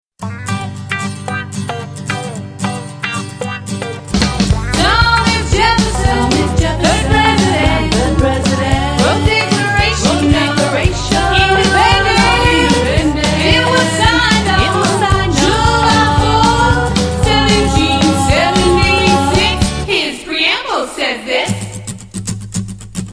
MP3 Demo Vocal Tracks